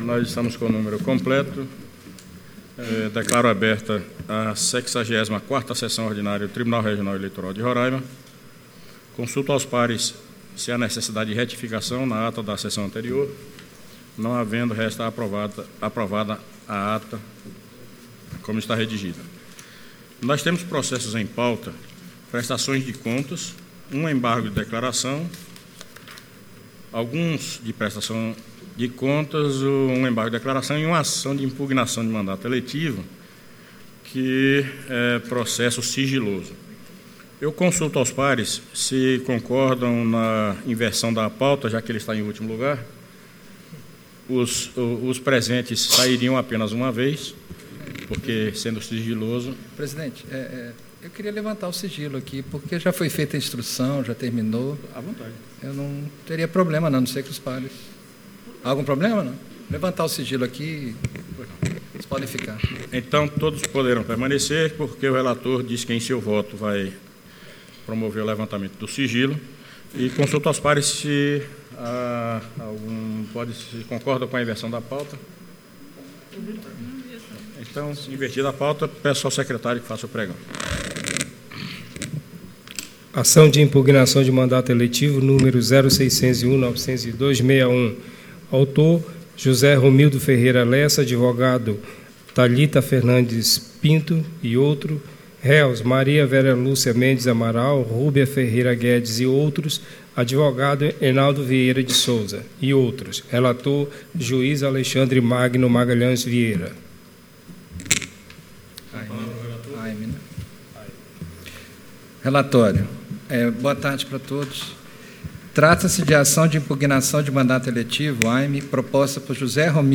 Áudio da 64ª Sessão Ordinária de 02 de setembro de 2019. Parte I